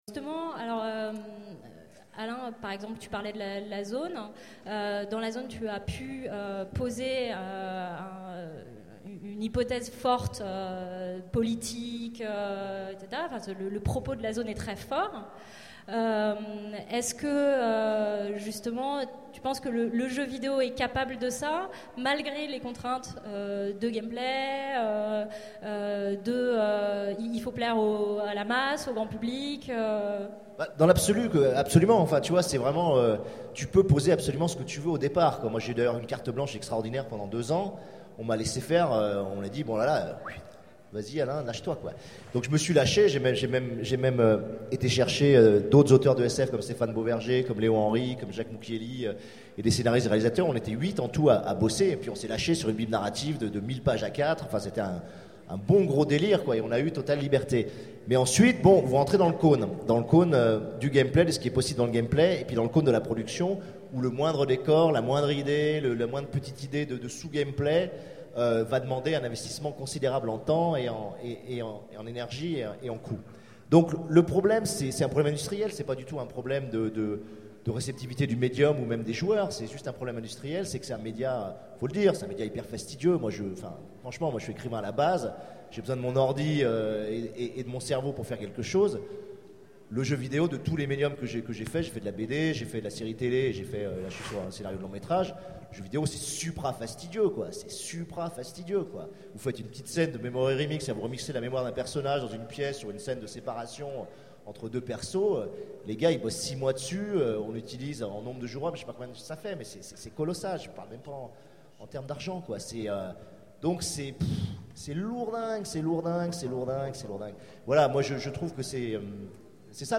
Utopiales 12 : Conférence Science-fiction et jeu vidéo